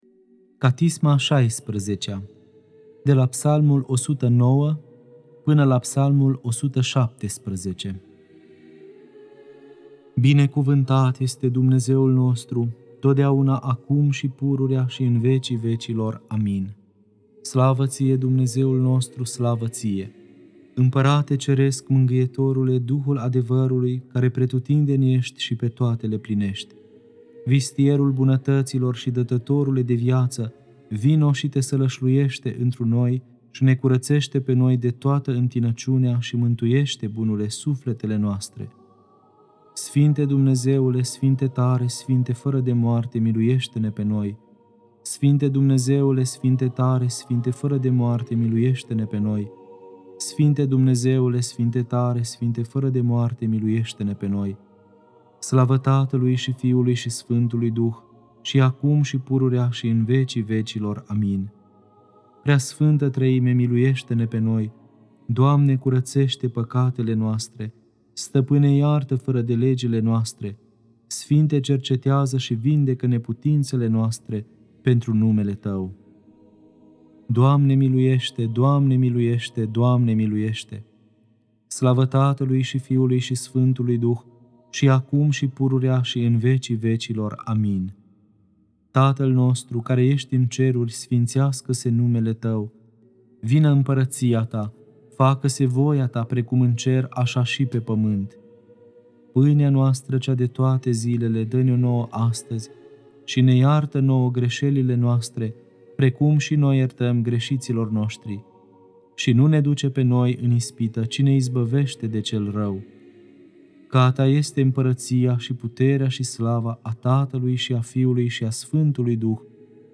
Catisma a XVI-a (Psalmii 109-117) Lectura